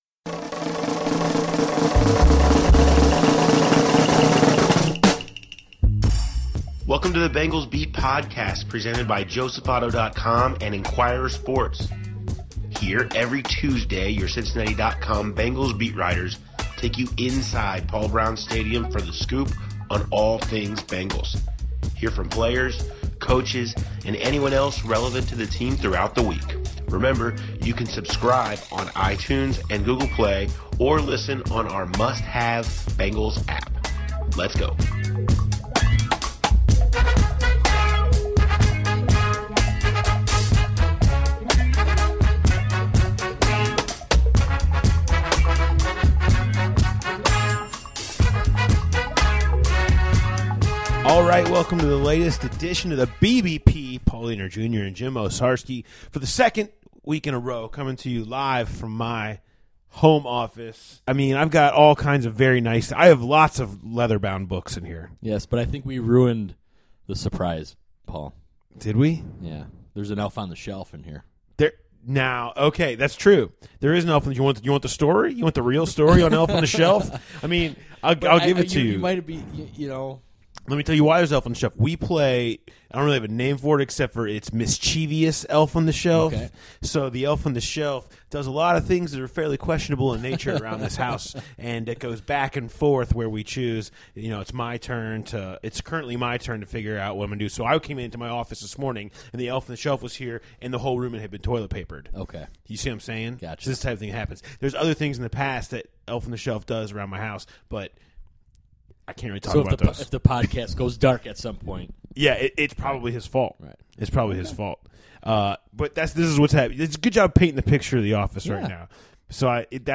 Bengals beat writers